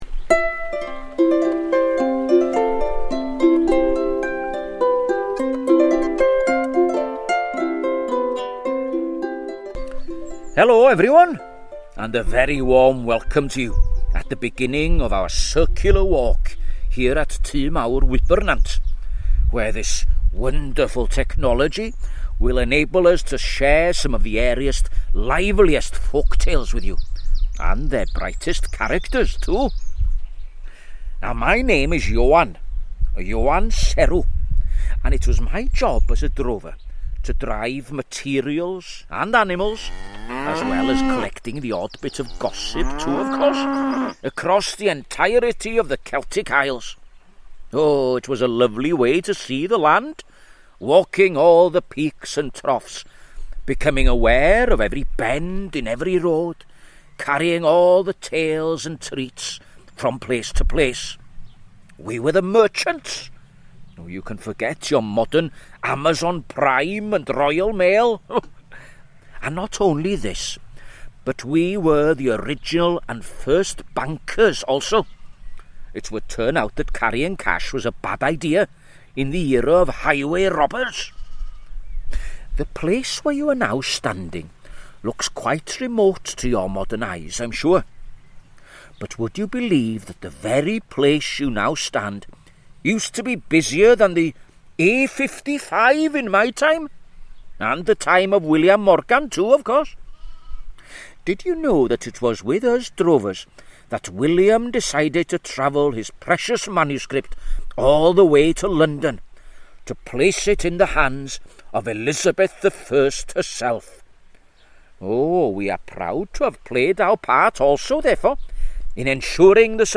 Ioan Serw, the drover, explains how he and his peers drove animals to markets all over the country back in the Tudor era, on foot!